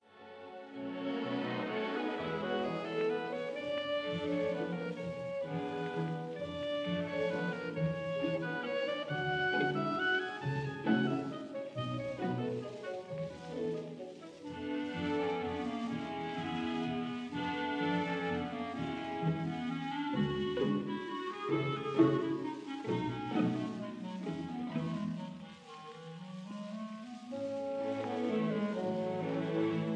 in E minor
This is the third marked Valse: Allegro moderato.